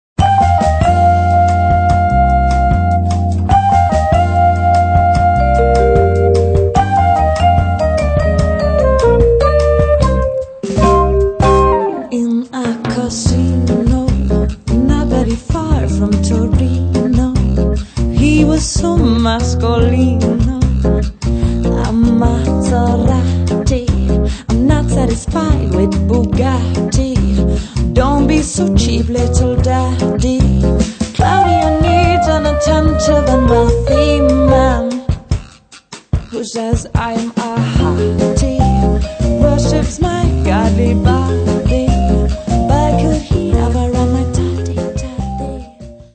ist ein zeitloser Clubsound